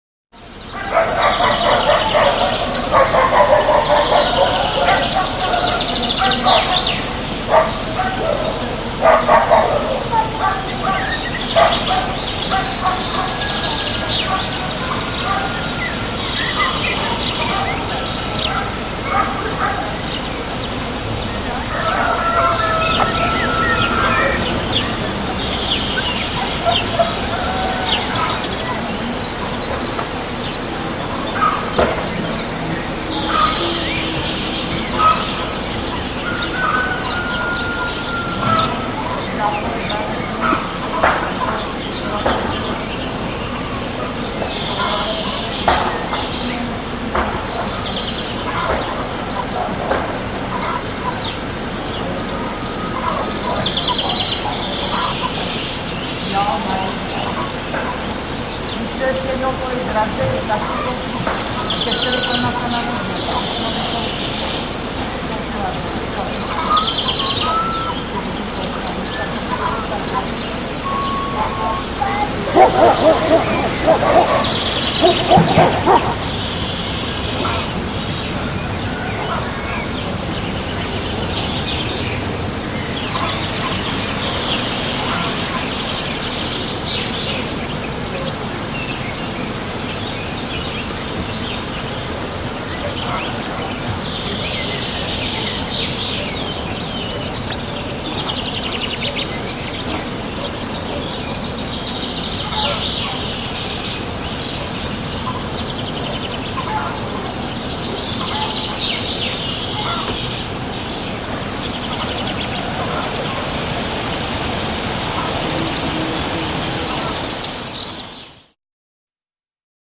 Village, Early Morning; Dogs Bark, Occaisional Distant Cock-crow And Villagers Chat, Light Birdsong And Wind In Trees